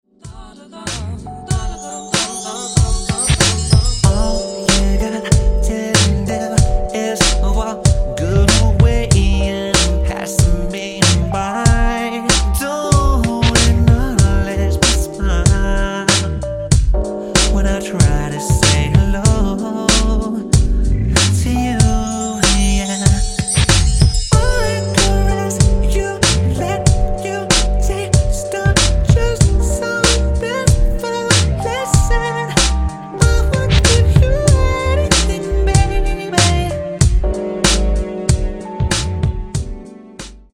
Authentic look, voice, and iconic dance moves